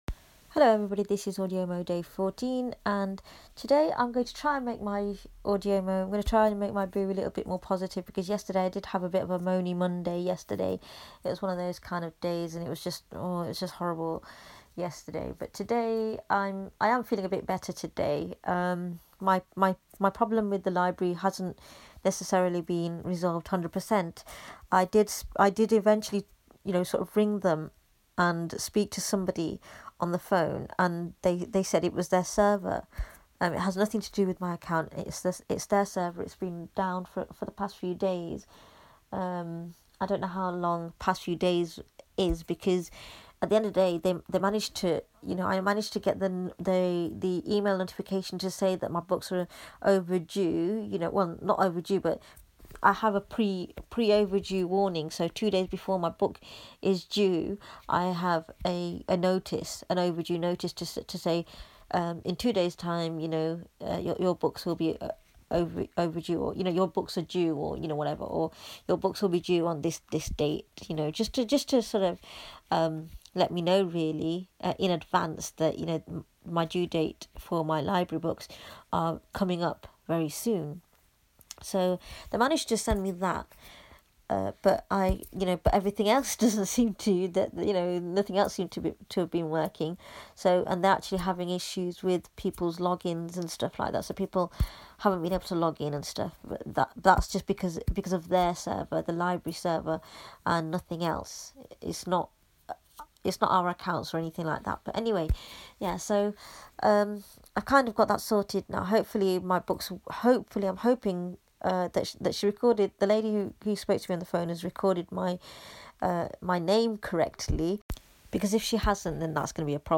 It got cut off half way. :/